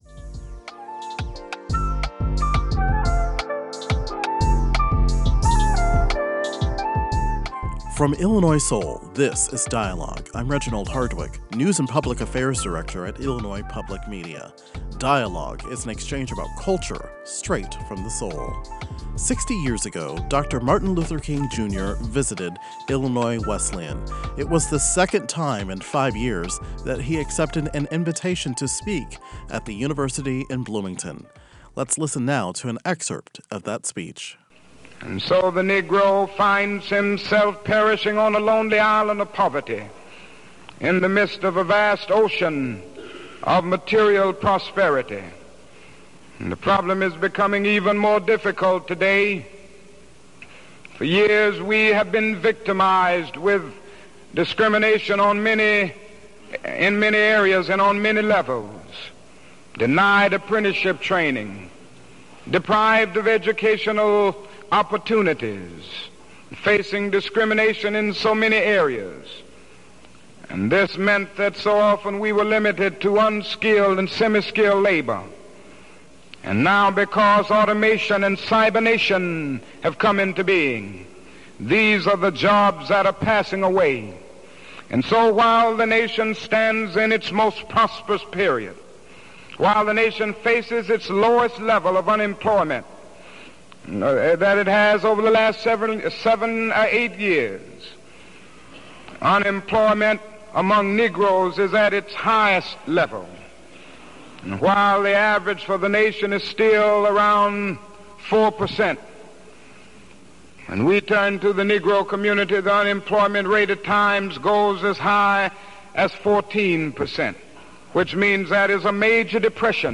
On February 10, 1966, three years after the March on Washington, Rev. Martin Luther King Jr. spoke on the campus of Illinois Wesleyan University in Bloomington.
We wanted to bring you an extended excerpt of that speech, acknowledging the uncanny nature of his arguments, as relevant to today.